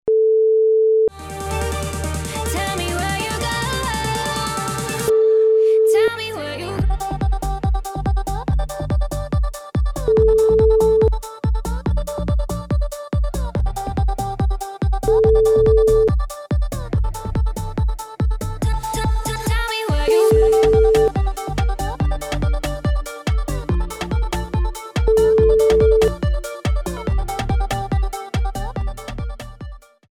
Easy Listening Dance